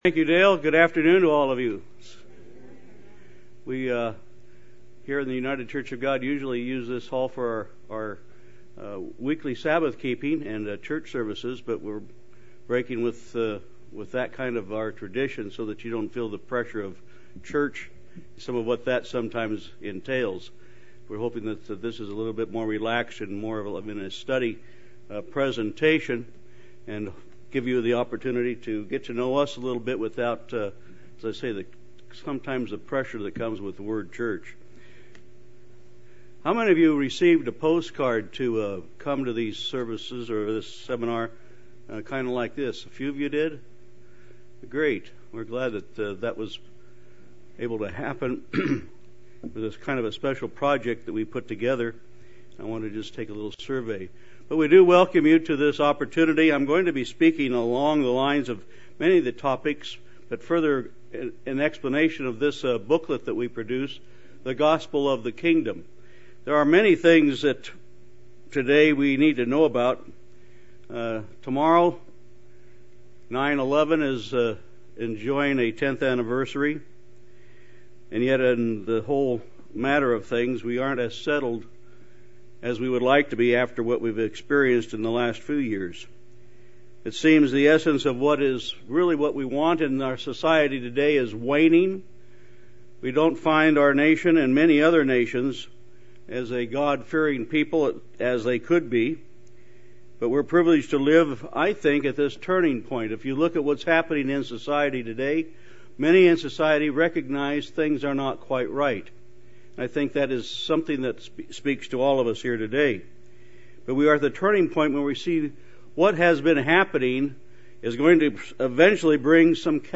What is the Kingdom of God and why does it matter to you, your life, and your nation? Where are we in Bible prophecy, and what is coming next? Learn more in this Kingdom of God seminar.